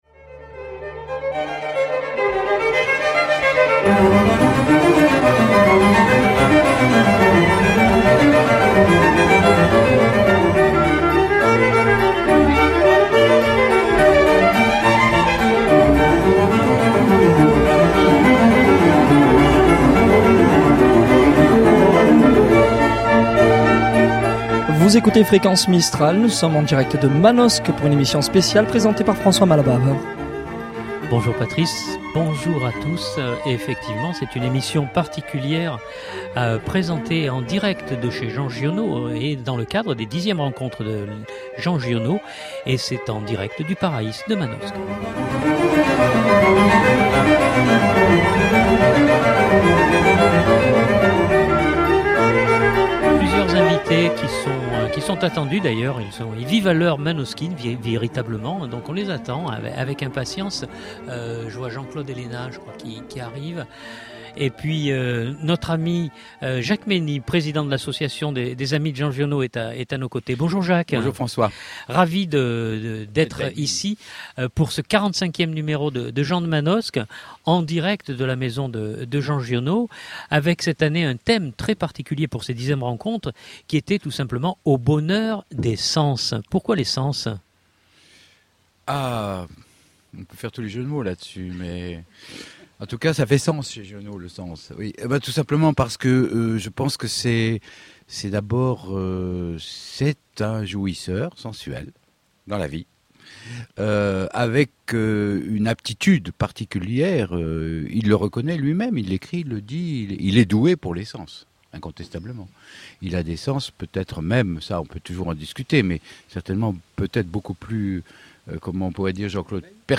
Pour la Xème édition des Rencontres Giono, Fréquence Mistral était en direct de la maison de l'écrivain pour une belle clôture du festival.